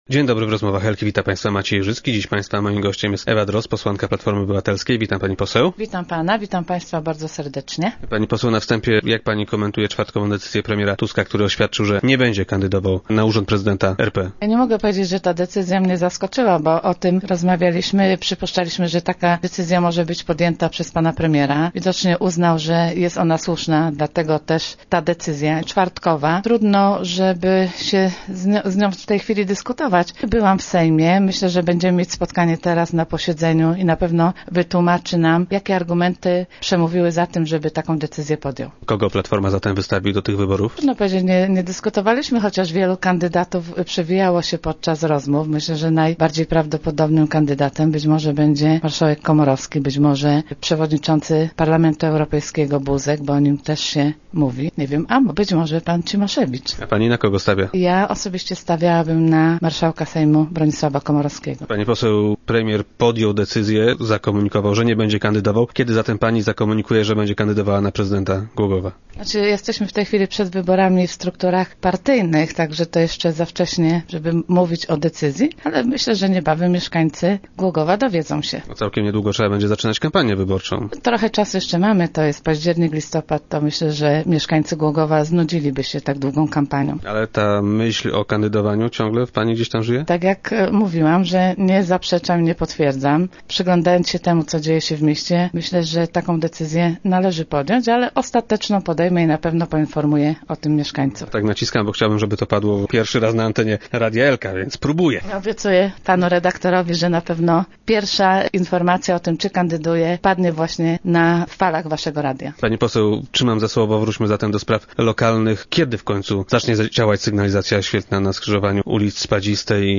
Trwają prace nad nowelizacją ustawy o ruchu drogowym. Gościem Rozmów Elki była posłanka Ewa Drozd, która tym tematem zajmowała się w Sejmie.